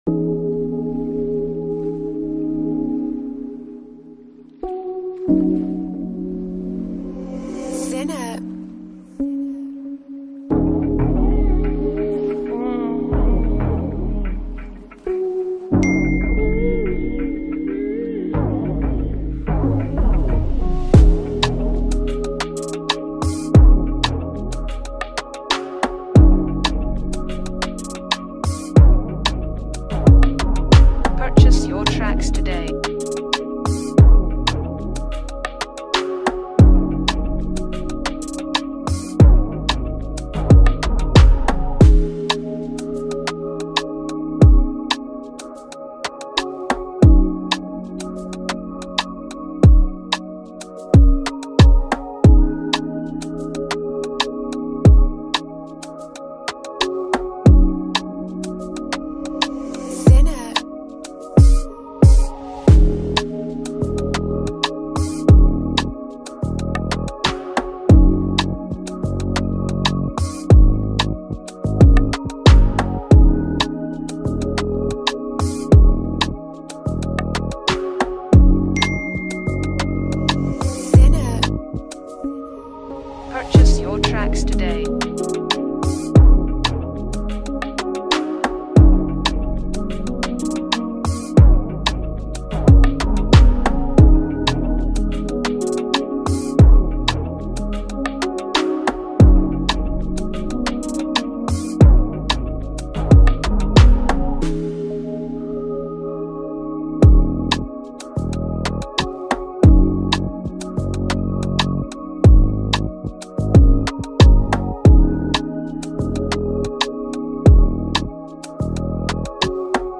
a cool and emotional Afro